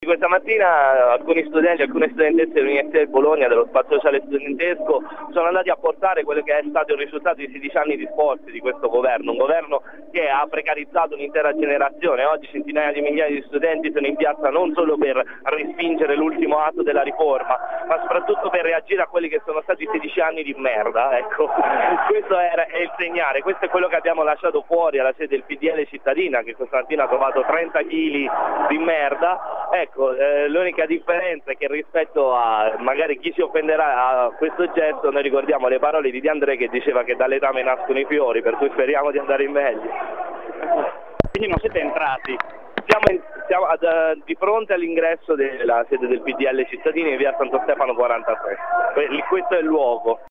Ascolta l’intervista: cacca